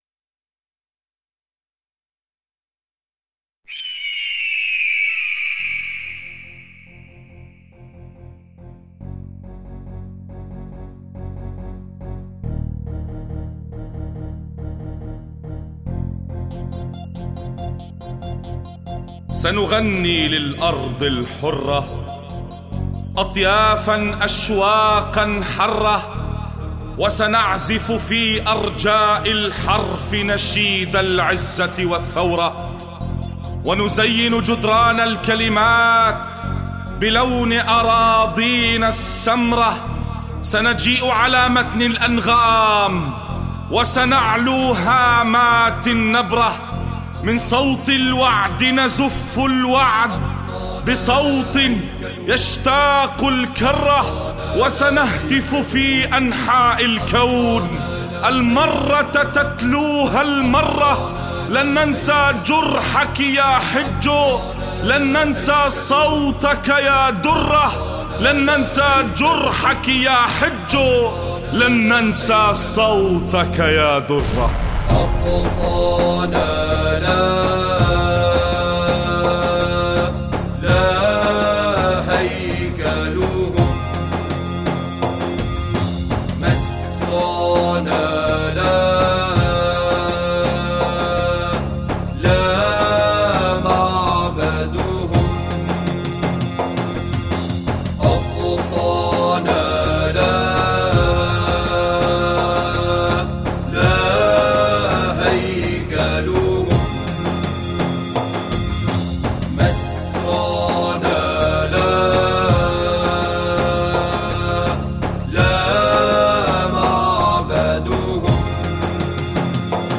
أناشيد فلسطينية... يا قدس إنا قادمون